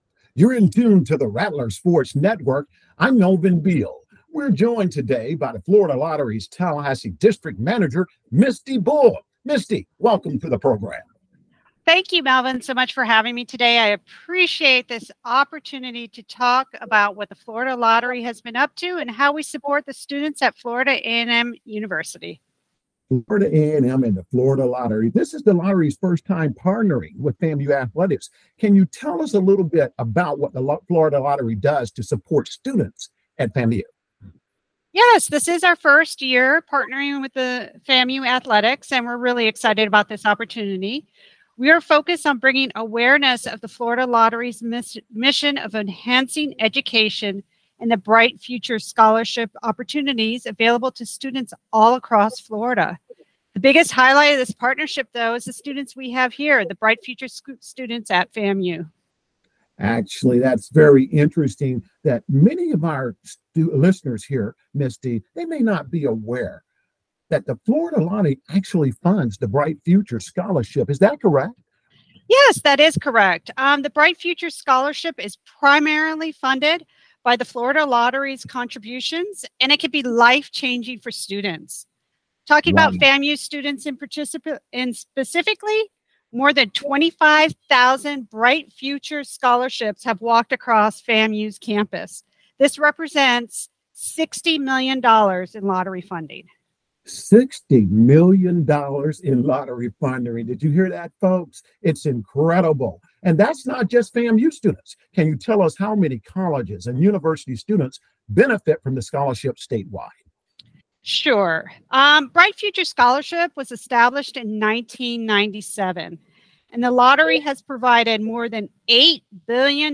Rattler Sports Network Interview with Florida Lottery